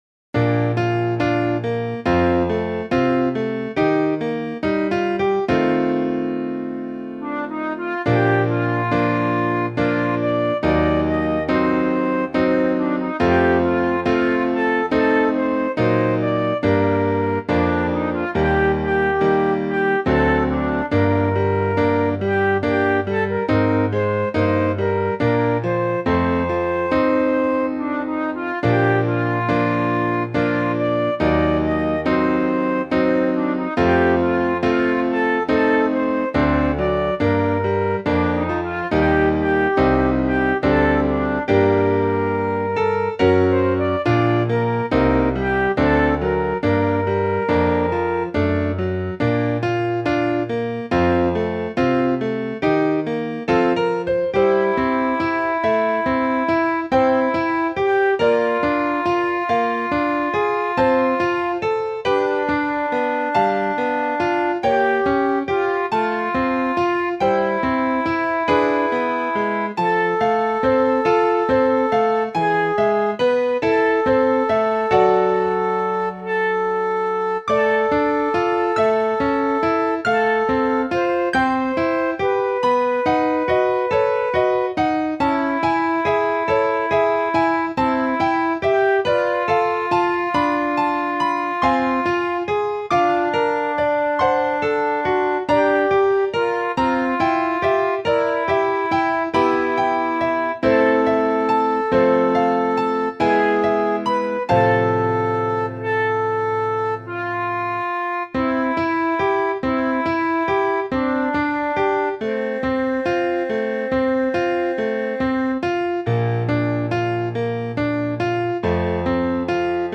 Voicing/Instrumentation: 2 part choir , Duet